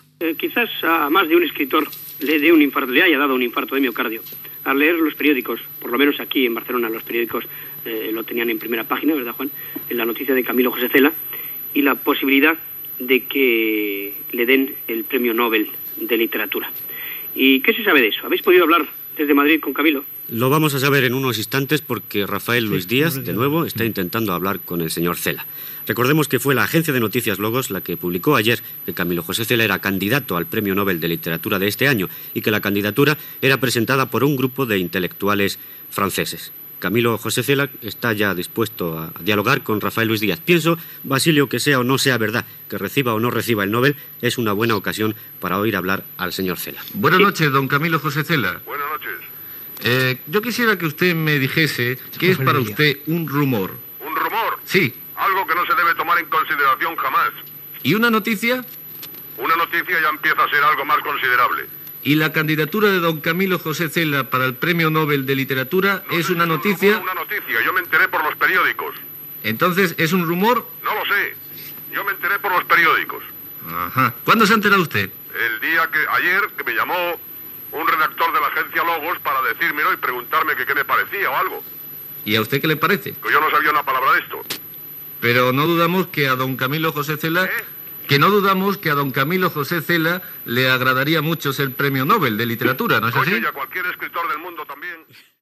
Entrevista a l'escriptor Camilo José Cela sobre el rumor que guanyarà el Premi Nobel de literatura.
Informatiu